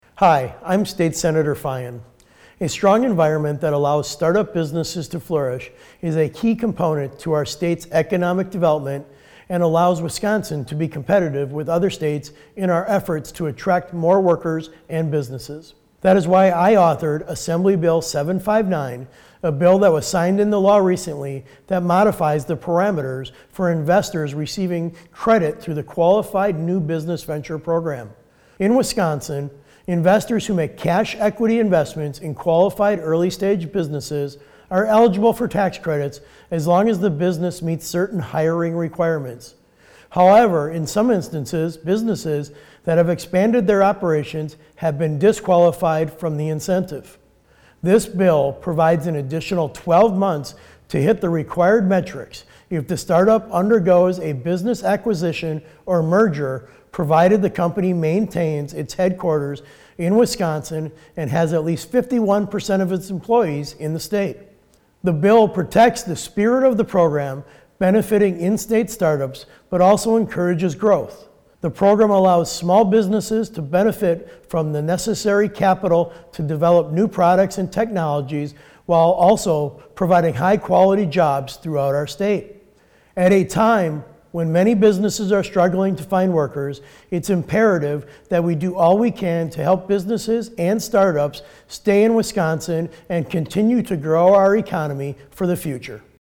Weekly GOP radio address: Sen. Feyen incentivizes startup investment - WisPolitics